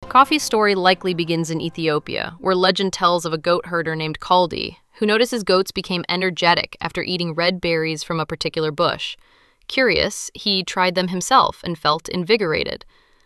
conversational_a.wav